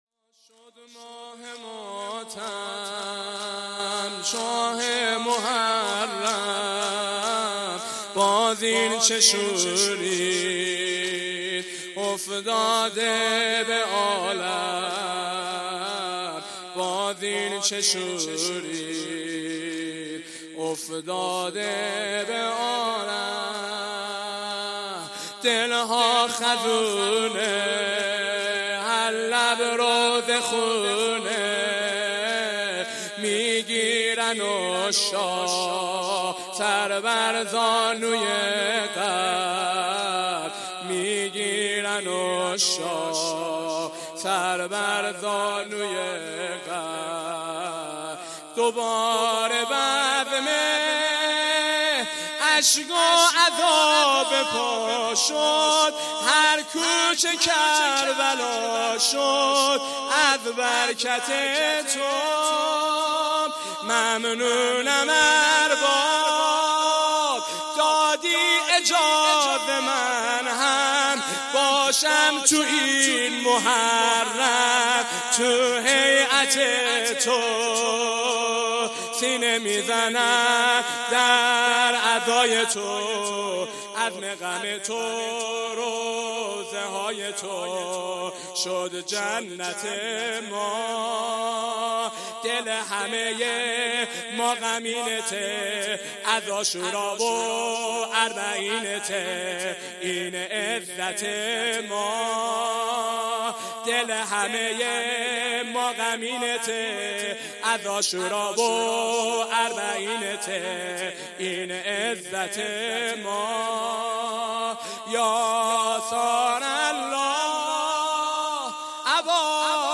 خیمه گاه - هیأت جوانان فاطمیون همدان - شب اول (مداحی واحد)
مسجد شهید مدرس